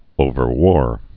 (ōvər-wôr)